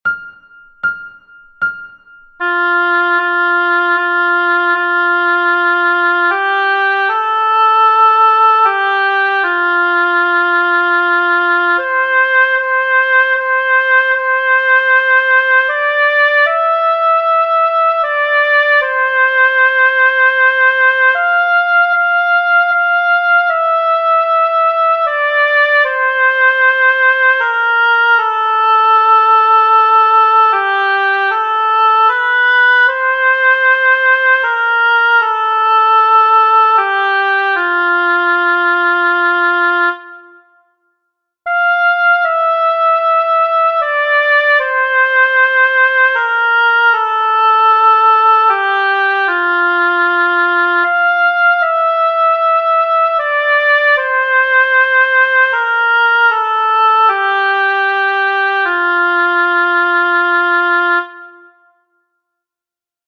Slow:
tenor-slower.mp3